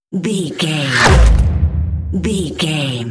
Sci fi whoosh to hit 630
Sound Effects
dark
futuristic
intense
tension